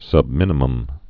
(sŭbmĭnə-məm, sŭb-mĭn-)